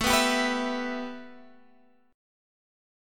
A7sus2 chord